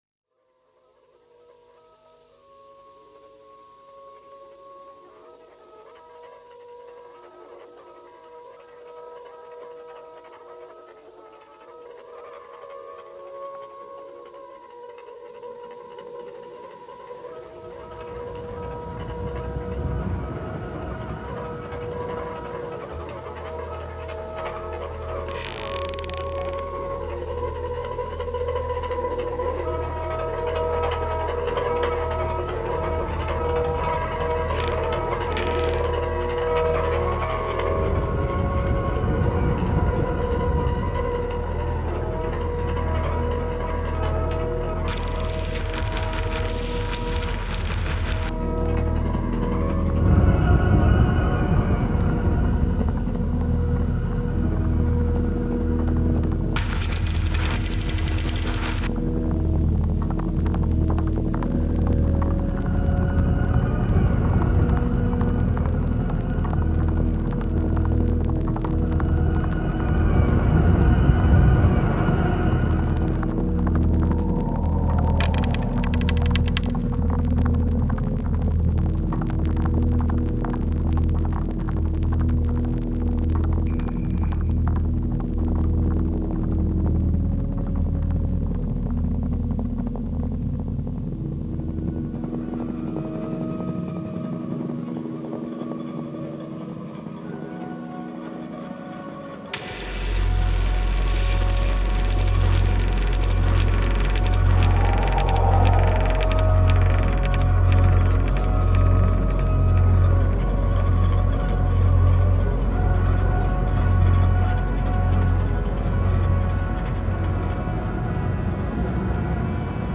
stereo version of the multichannel sound installation
Sound Art